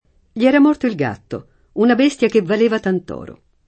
l’ $ra m0rto il g#tto, una b%StLa ke vval%va tant 0ro] (Verga) — elis. frequente nella locuz. tant’è vero che…